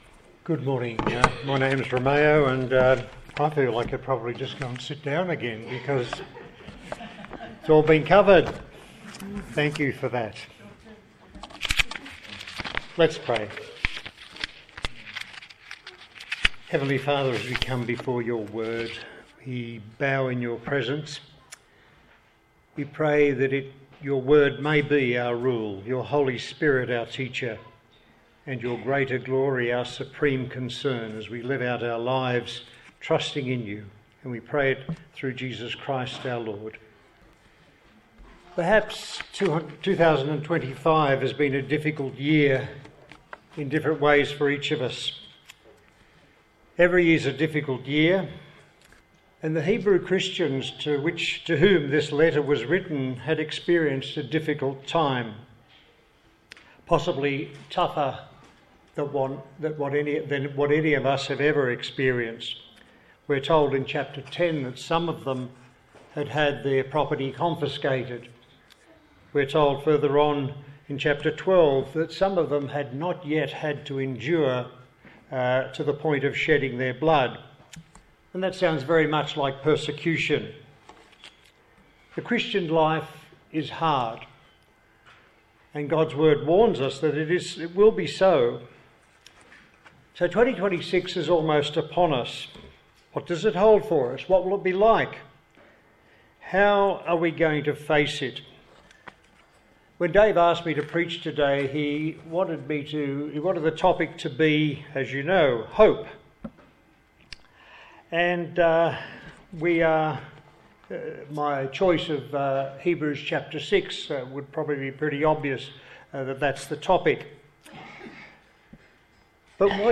Hebrews Passage: Hebrews 6:11-20 Service Type: Sunday Morning